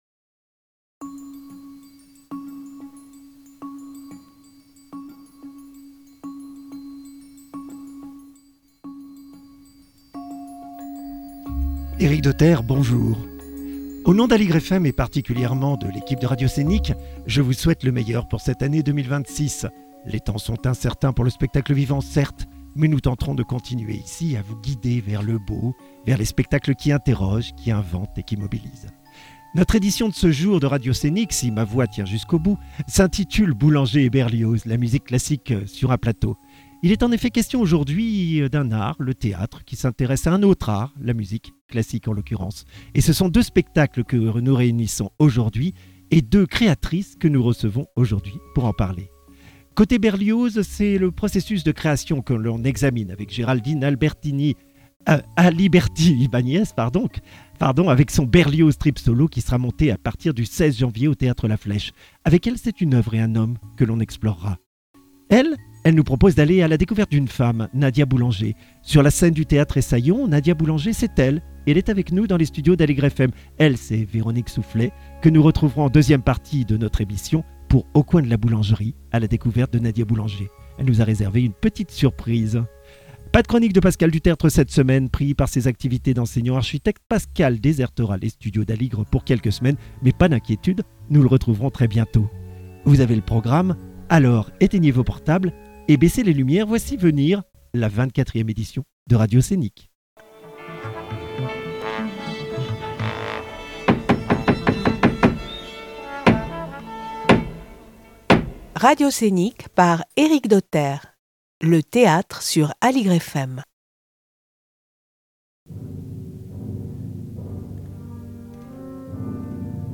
Nos extraits sonores et musicaux